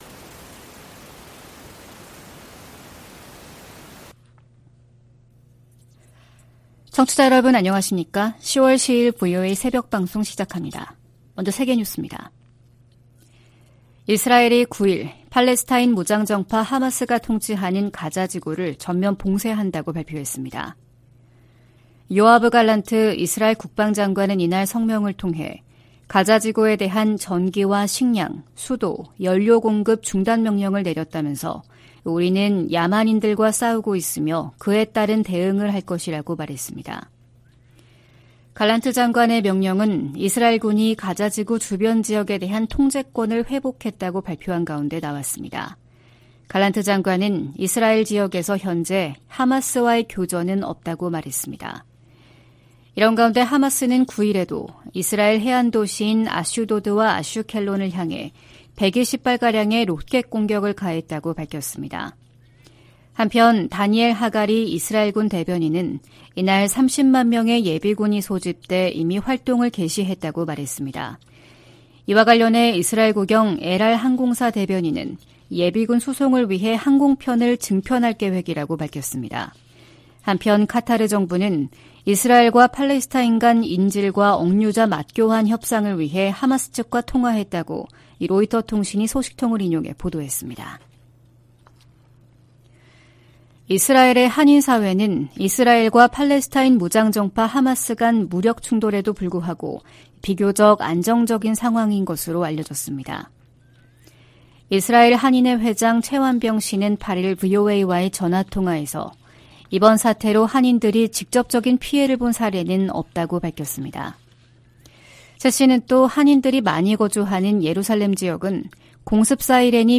VOA 한국어 '출발 뉴스 쇼', 2023년 10월 10일 방송입니다. 미국 정부는 한 때 한국에 동결됐던 이란 자금의 ‘하마스 유입설’을 강하게 부인했습니다.